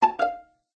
two_tones_quick.ogg